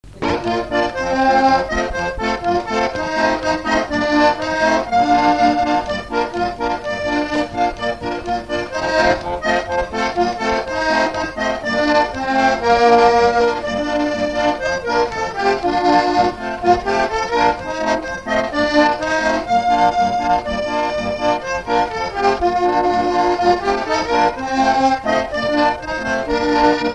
Instrumental Fonction d'après l'analyste gestuel : à marcher ; Usage d'après l'analyste gestuel : danse
Pièce musicale inédite